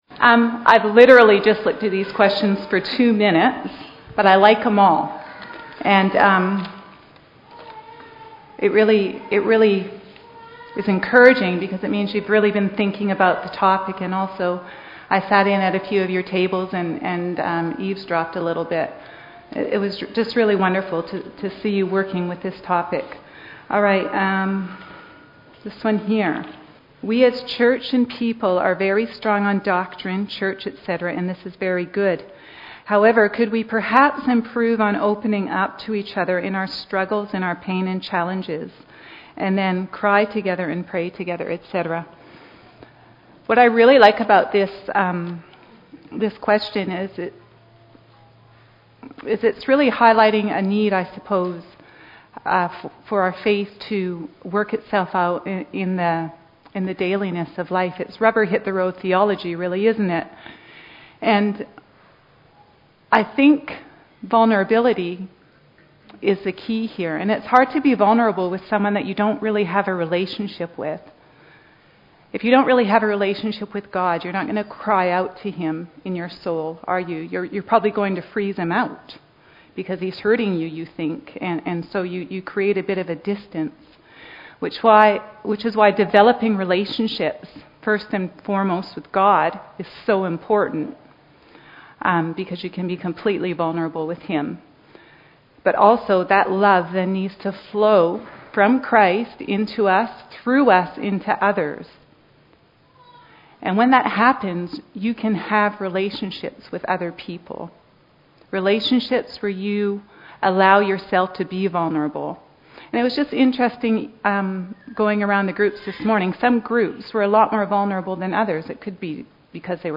Godly Woman, Godly Growth League Day Question & Answer Session